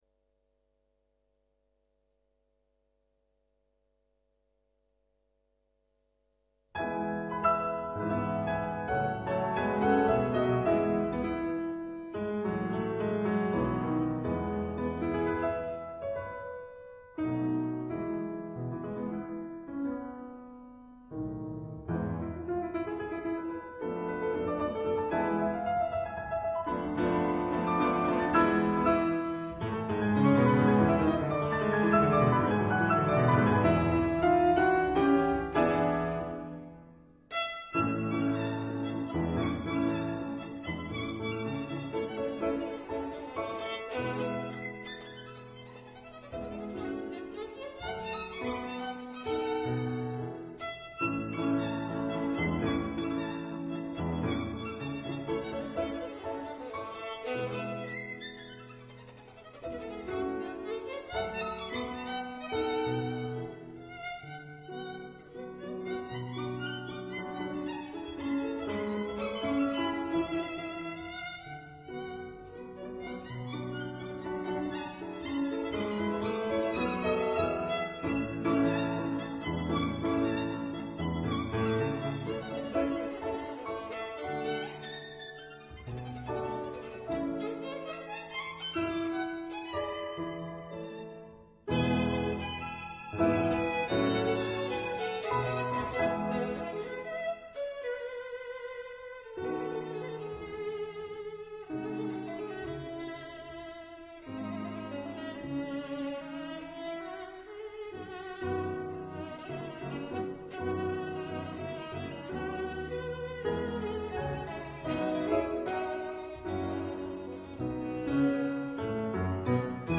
arr. for violin and piano